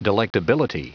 Prononciation audio / Fichier audio de DELECTABILITY en anglais
Prononciation du mot : delectability
delectability.wav